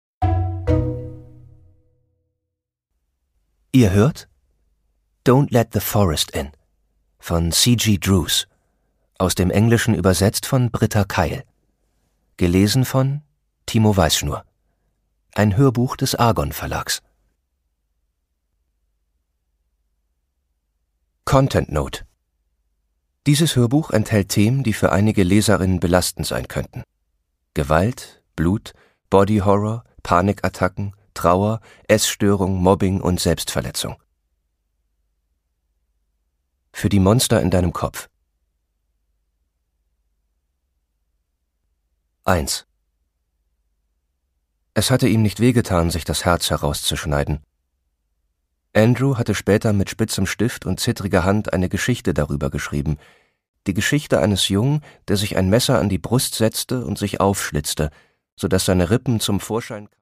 Produkttyp: Hörbuch-Download
erzählt melancholisch verträumt und düster fesselnd das romantische Schauermärchen. Dabei lotet er die psychologischen Tiefen gekonnt und einfühlsam aus.